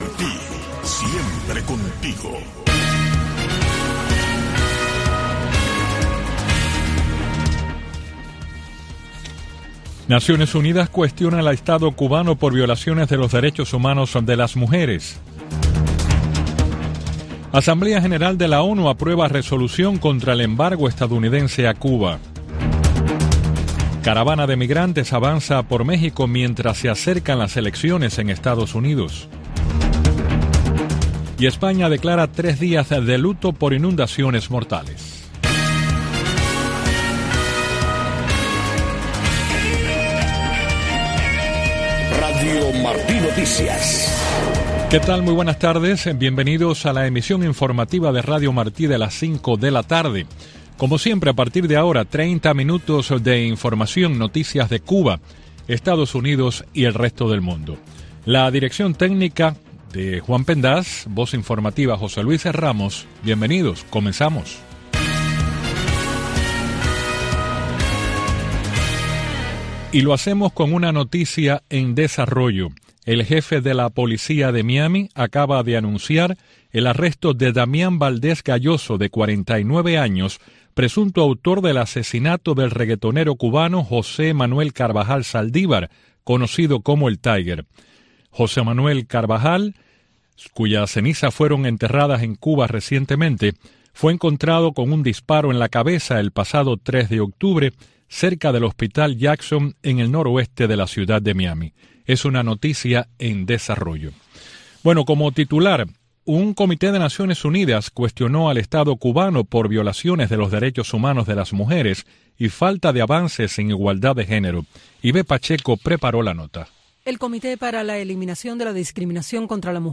Noticiero de Radio Martí 5:00 PM